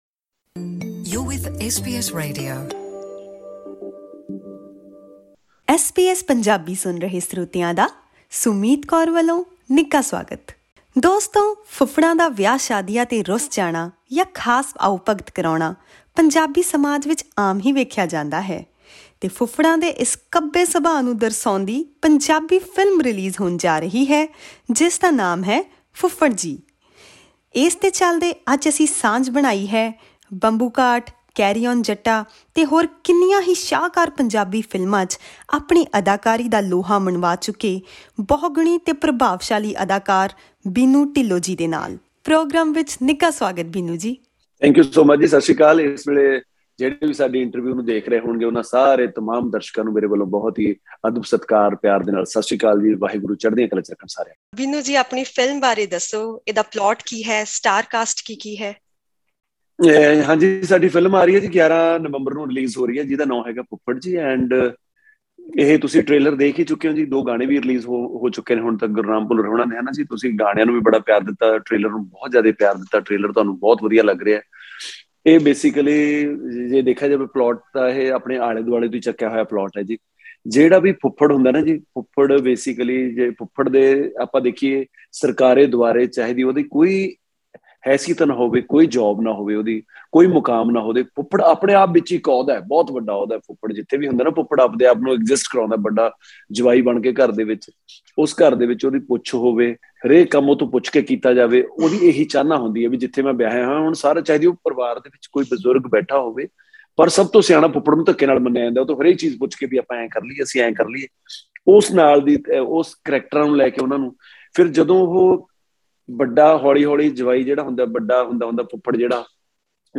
Ahead of the release of his upcoming film, 'Fuffad Ji', Punjabi actor best known for his comic roles, Binnu Dhillon, talks about movie genres and his characters in the forthcoming projects.